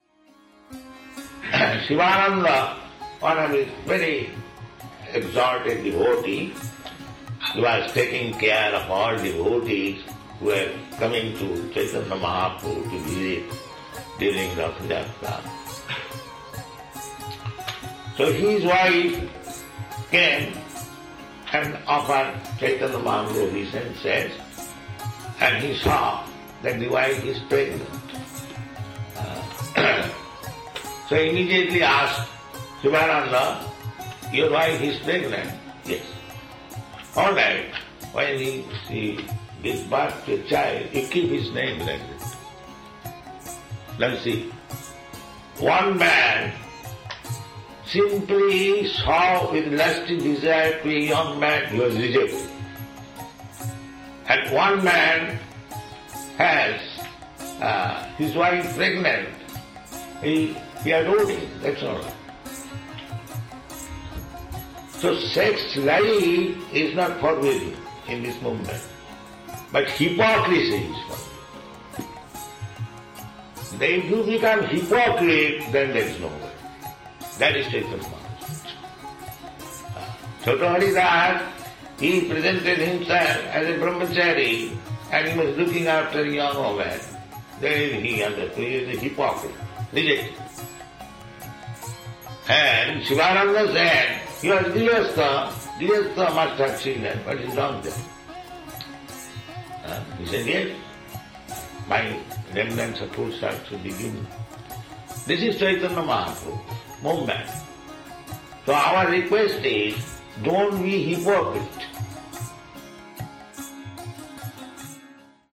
(760523 - Lecture SB 06.01.23 - Honolulu)